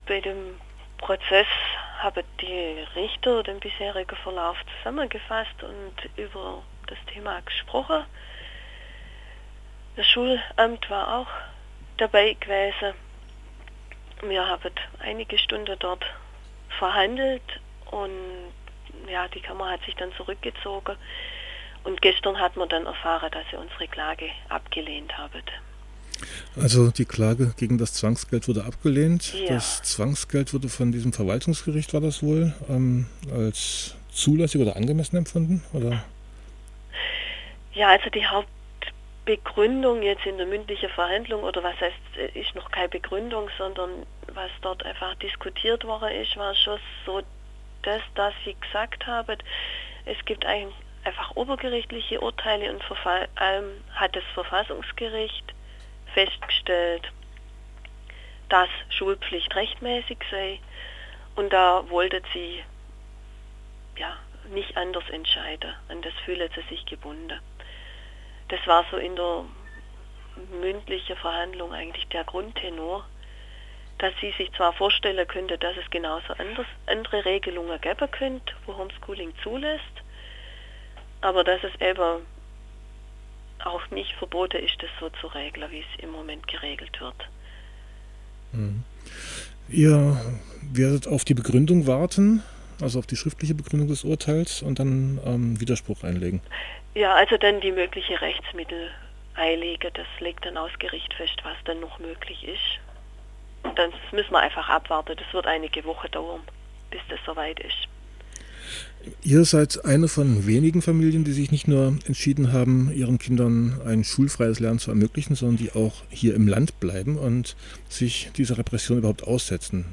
Abmoderation: Radio Dreyeckland hat am 8.